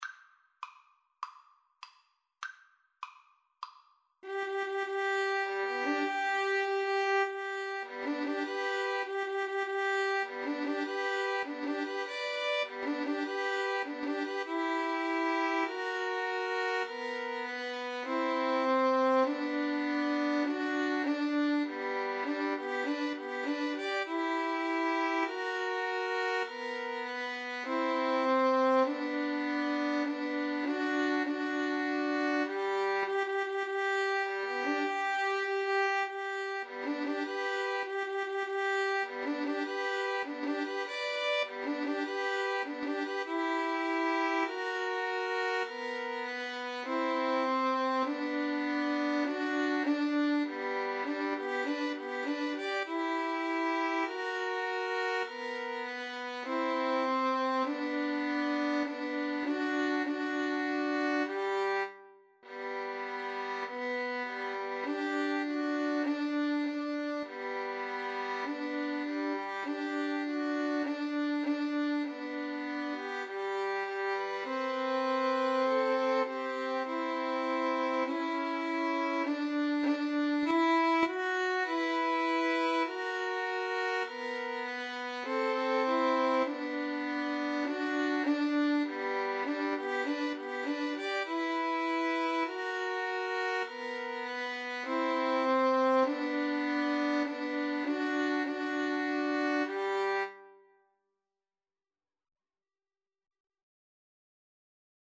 Andante maestoso =100
Violin Trio  (View more Easy Violin Trio Music)
Classical (View more Classical Violin Trio Music)